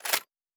Blowback Tick.wav